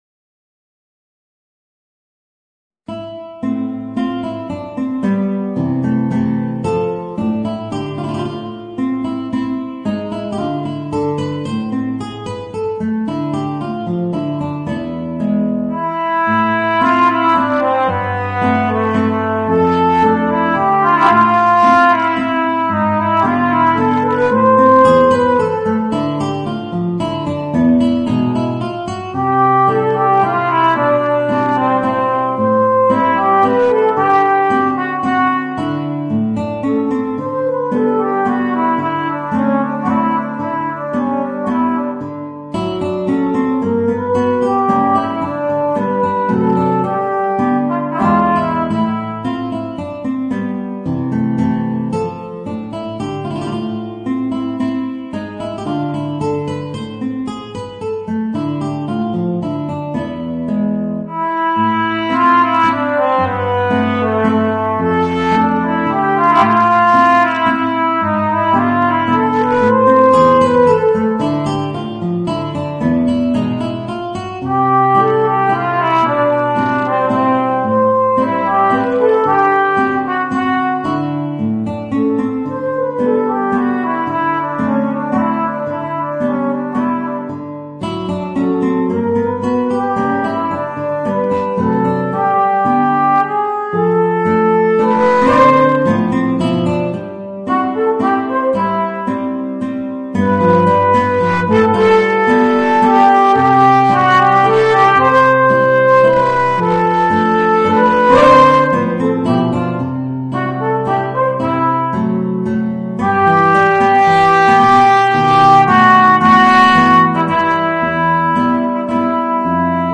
Voicing: Guitar and Alto Trombone